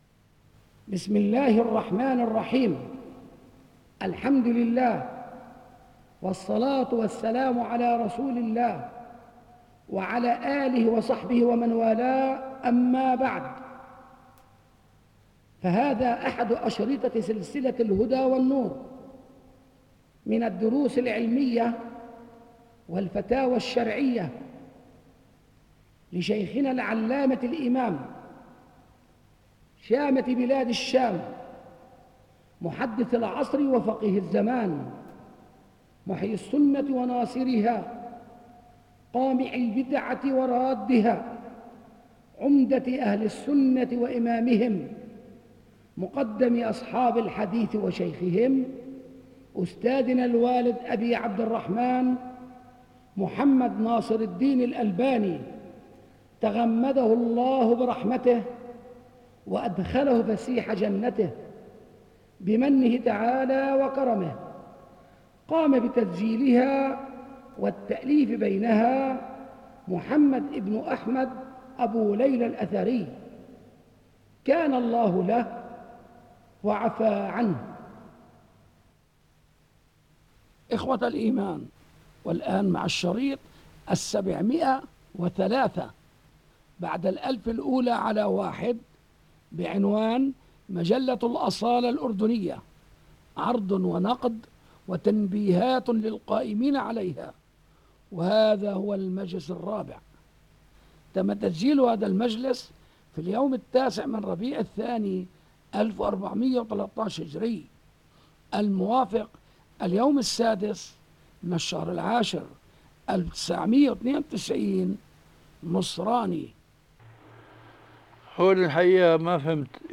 بِصوتِ الإِمامِ الألبَانِي